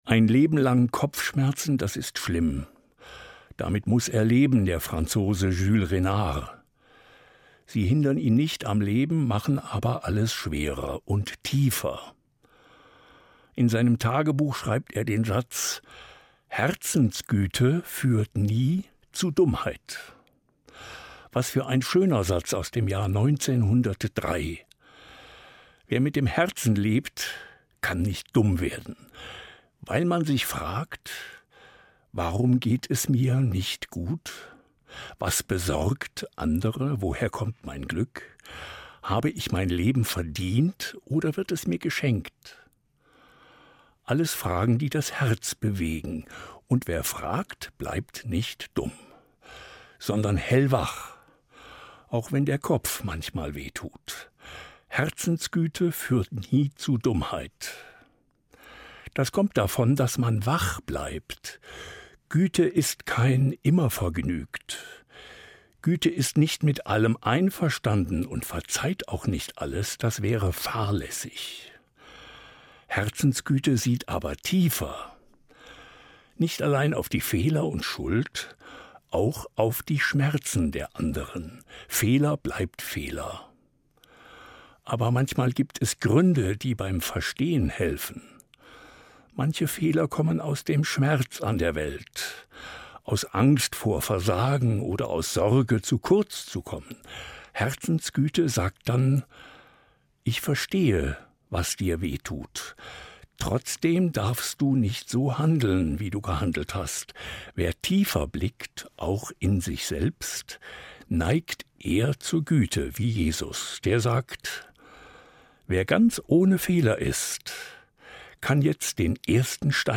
Evangelischer Pfarrer, Kassel